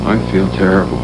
I Feel Terrible Sound Effect
Download a high-quality i feel terrible sound effect.